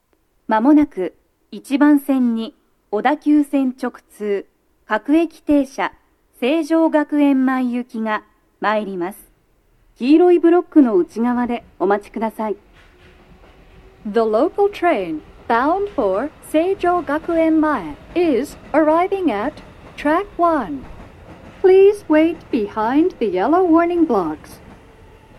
鳴動中に入線してくる場合もあります。
女声
接近放送2